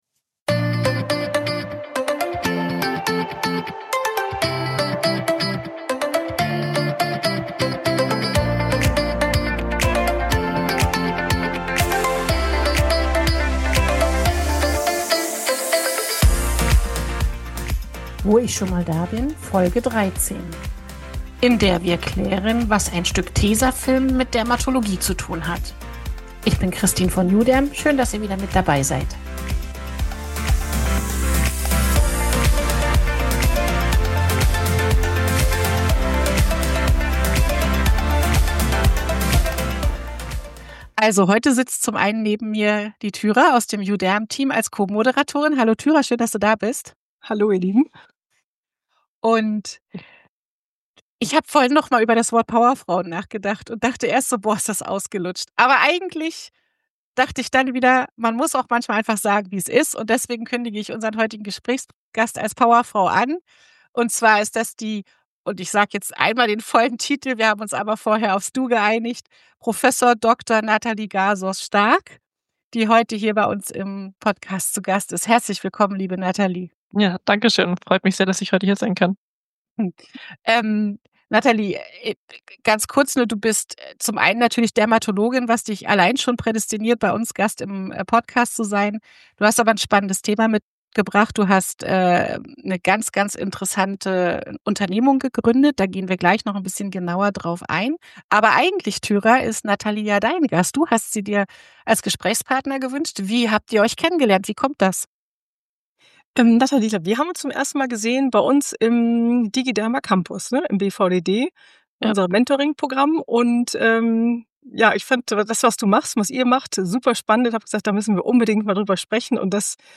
Mit leuchtenden Augen und dieser Leidenschaft in der Stimme erzählt unser Podcast-Gast von ihrem mehrfach mit Preisen überhäuften Projekt.